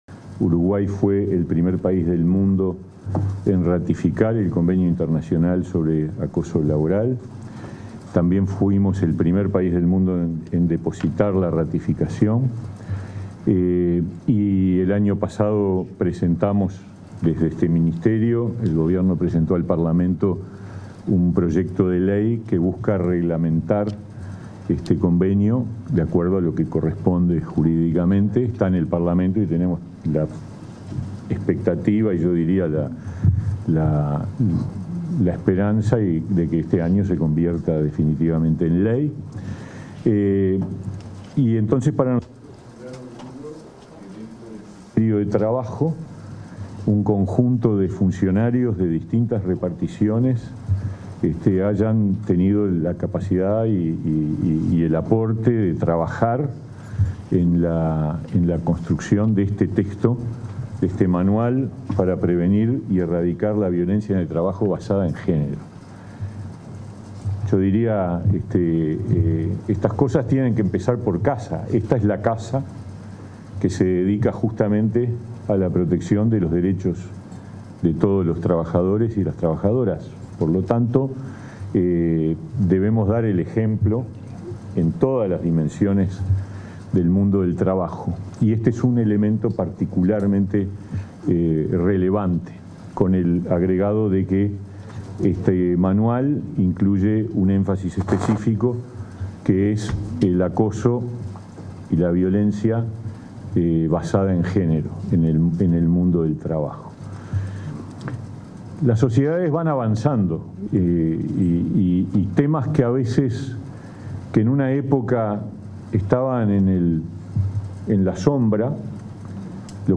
Palabras del ministro de Trabajo y la directora de Inmujeres
En el marco del lanzamiento de un manual de prevención de la violencia basada en género en el ámbito laboral, este 30 de marzo, se expresaron el